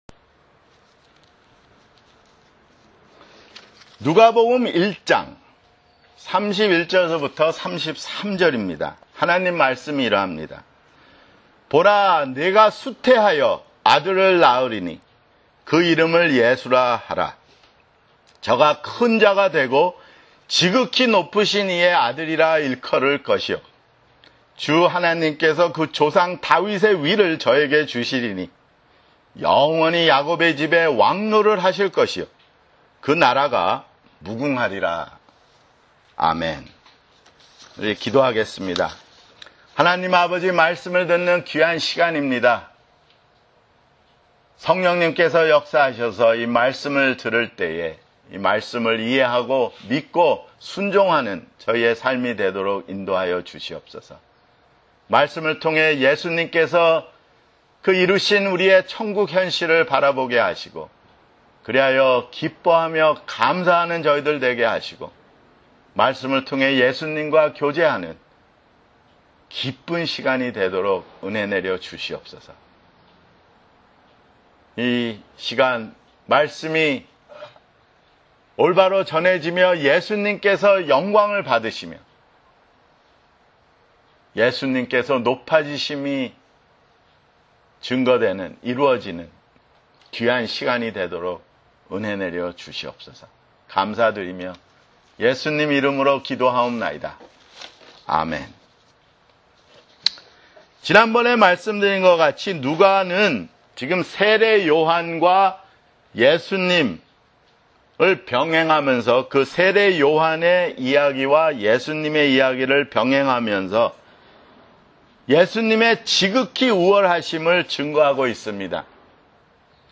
[주일설교] 누가복음 (6)